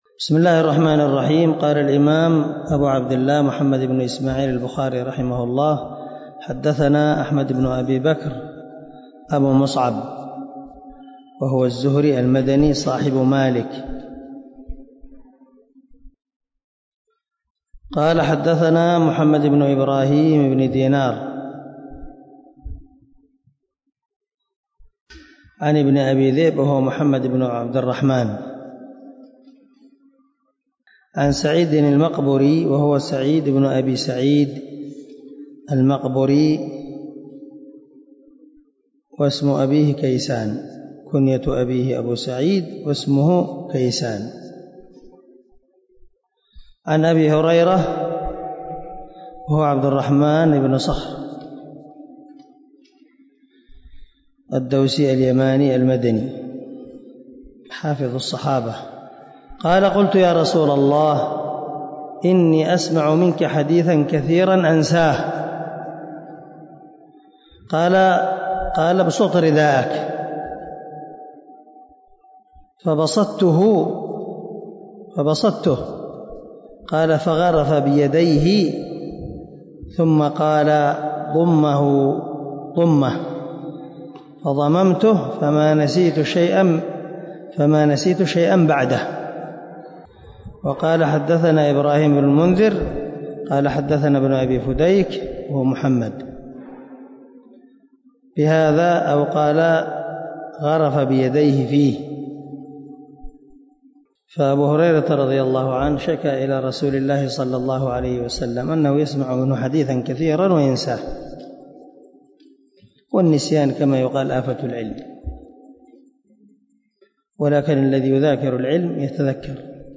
112الدرس 57 من شرح كتاب العلم حديث رقم ( 119 ) من صحيح البخاري
دار الحديث- المَحاوِلة- الصبيحة.